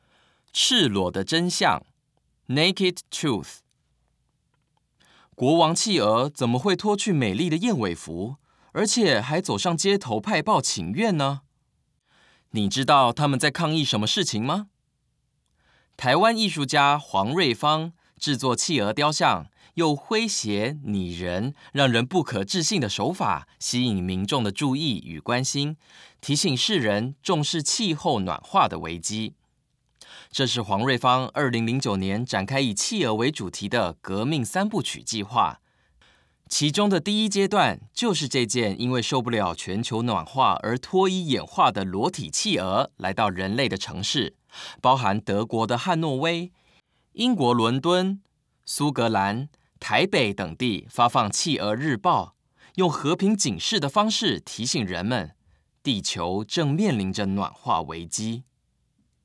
語音導覽